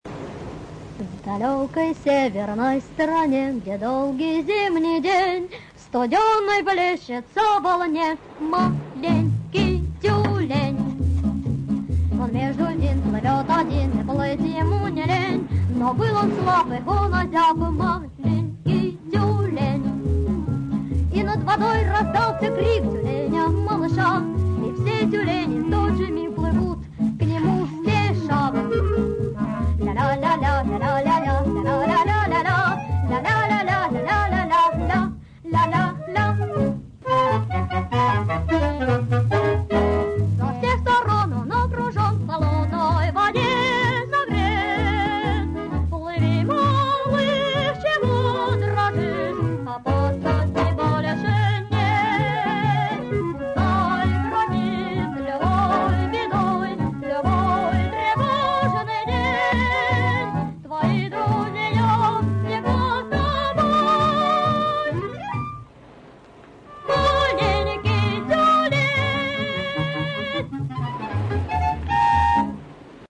Не подскажите, как их почистить от шума?